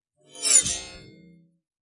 描述：金属效果使用台虎钳固定锯片和一些工具来击打，弯曲，操纵。 所有文件都是96khz 24bit，立体声。
Tag: 研磨 尖叫 金属 耐擦 效果 声音